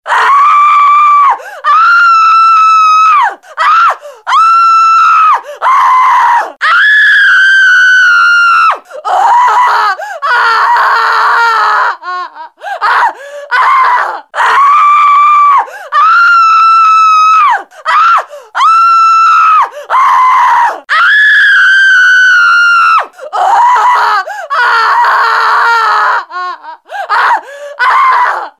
دانلود آهنگ جیغ 2 از افکت صوتی انسان و موجودات زنده
دانلود صدای جیغ 2 از ساعد نیوز با لینک مستقیم و کیفیت بالا
جلوه های صوتی